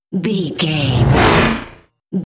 Whoosh electronic fast
Sound Effects
Atonal
Fast
futuristic
intense
sci fi